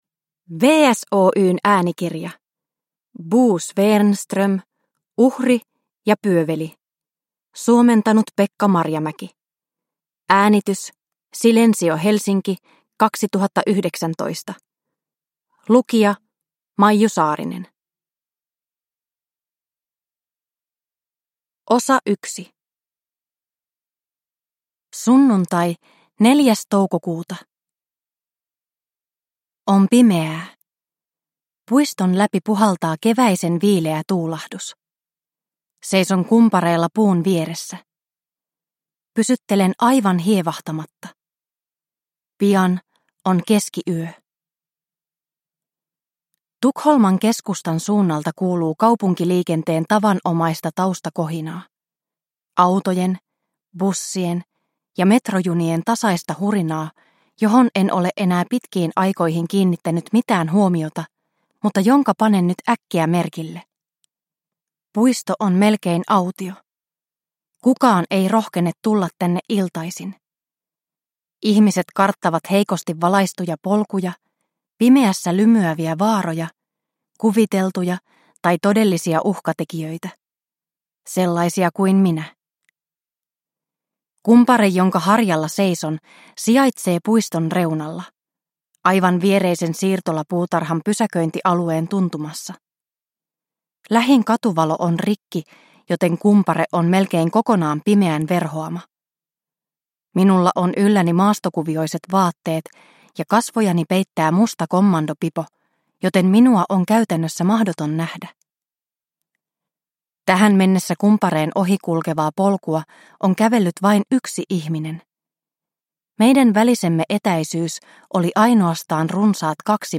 Uhri ja pyöveli – Ljudbok – Laddas ner